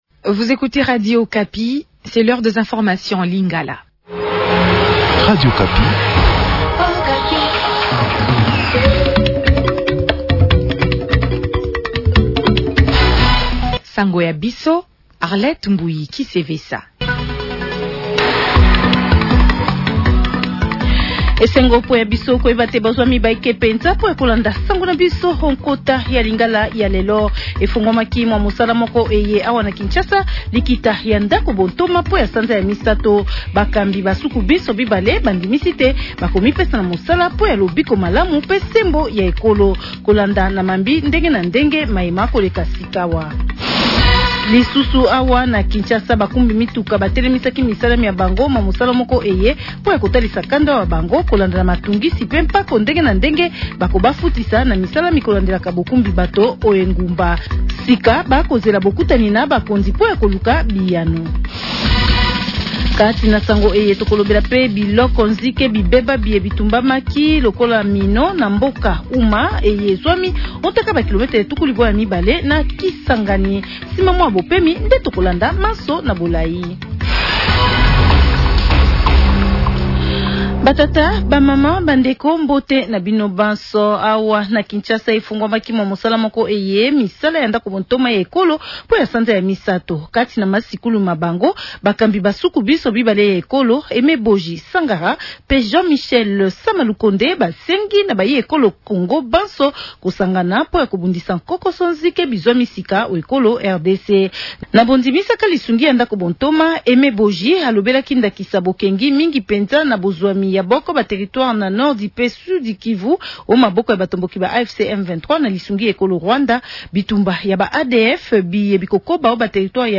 Journal Lngala Soir